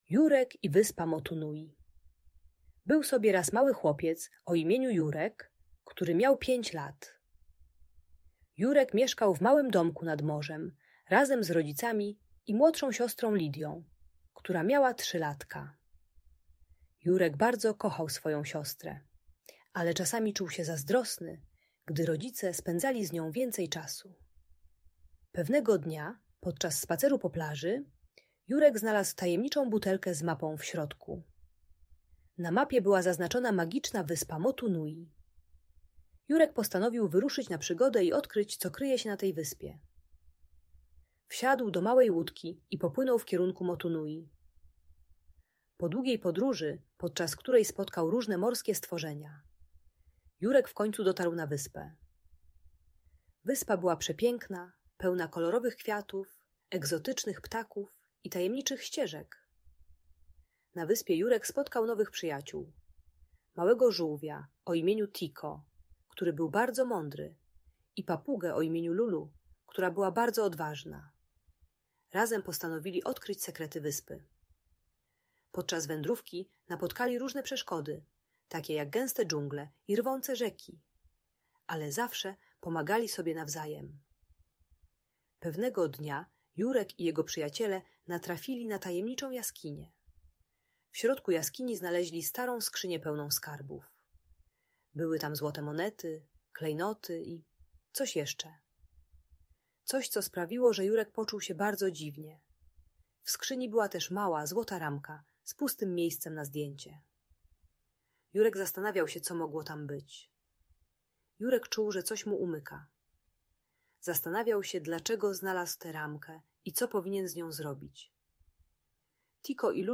Historia Jurka i Wyspy Motunui - Rodzeństwo | Audiobajka